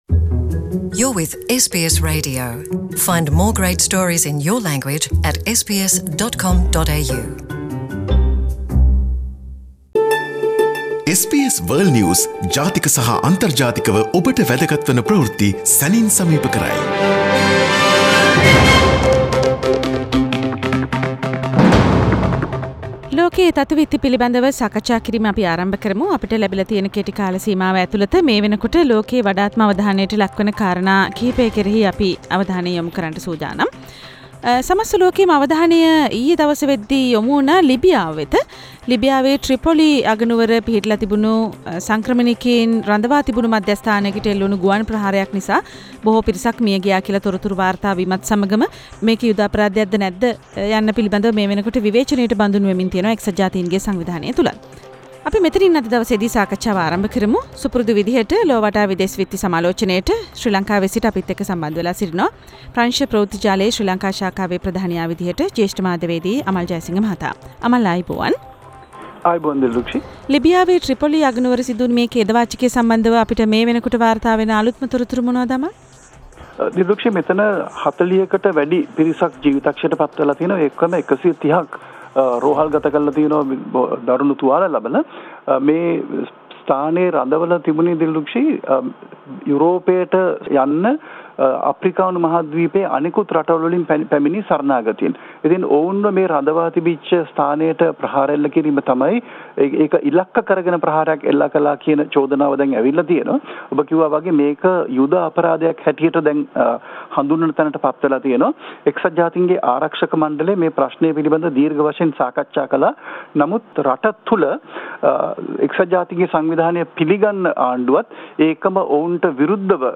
World news highlights Source: SBS Sinhala